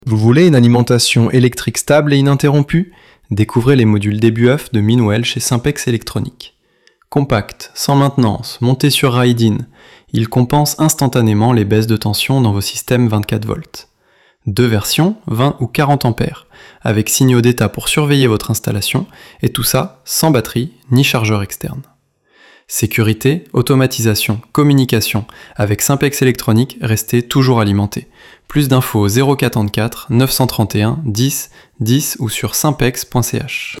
6001_ElevenLabs_Text_to_Speech_audio_Simpex.mp3